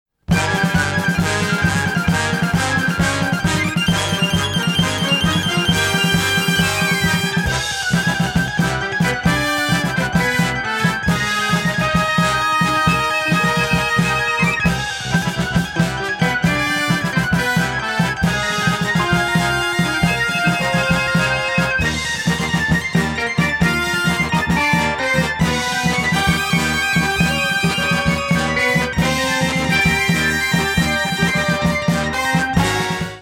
monaural sound from master tapes